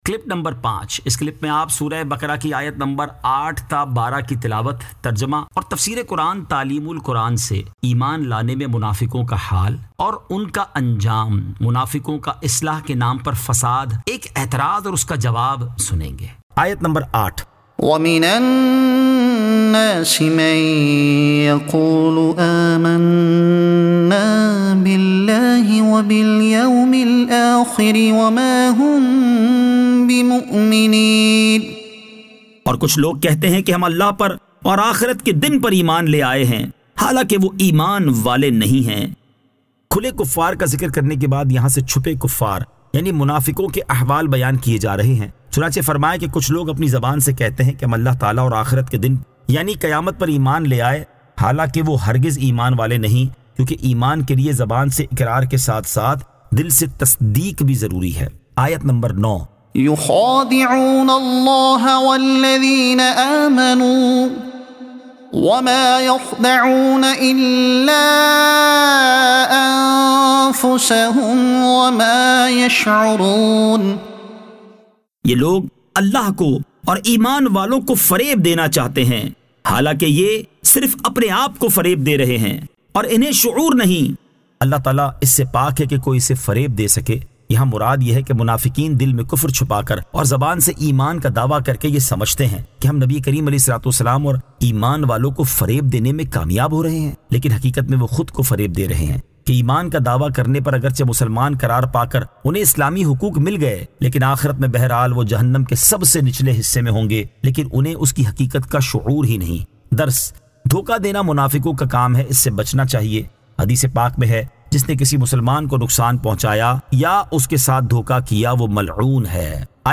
Surah Al-Baqara Ayat 08 To 12 Tilawat , Tarjuma , Tafseer e Taleem ul Quran